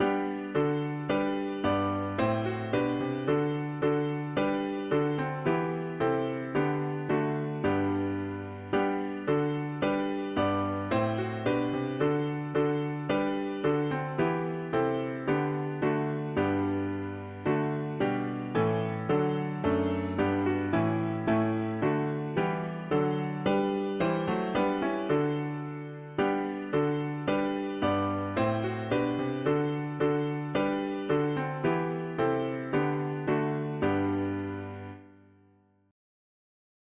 Christ the head, and we h… english christian 4part chords
1735 Key: G major Meter